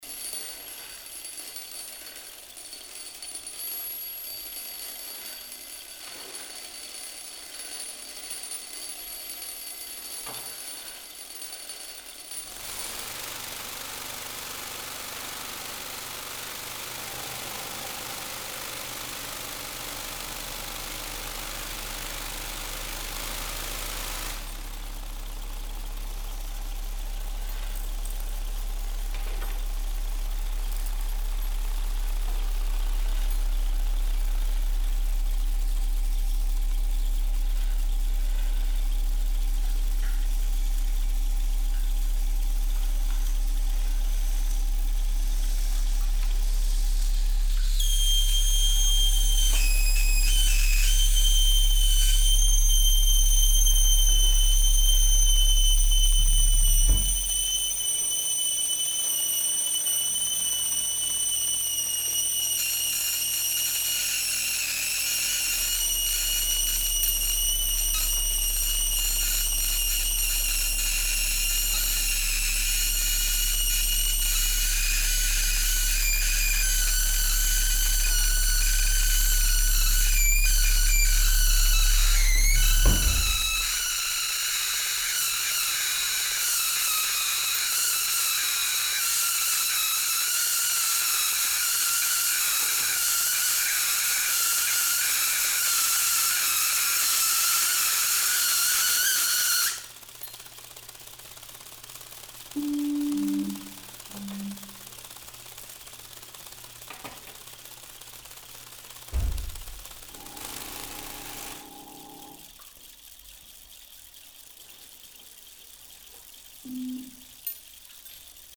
turntable without cartridge
remodeled counters, selfmade objects, tuning fork
speaker and piezo vibration
A nice sense of the space of the room.